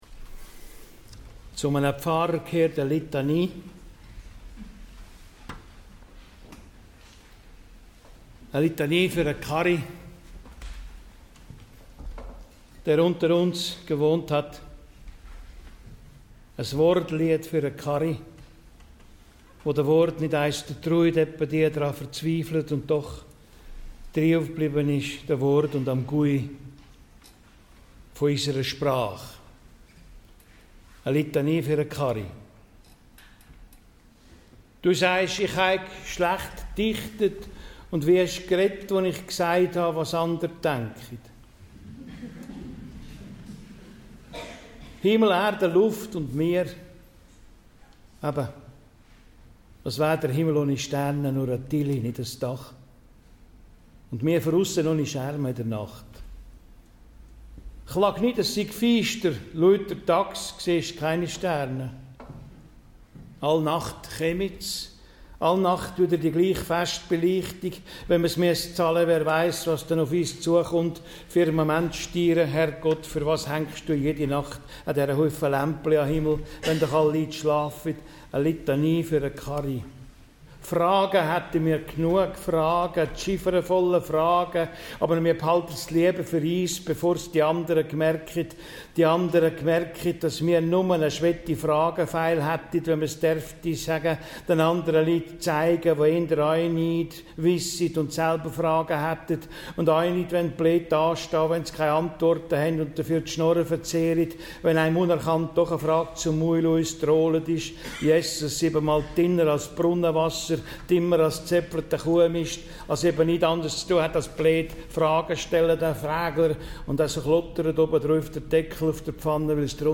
16.00 und 20.00 Uhr, Theater Altes Gymnasium Sarnen.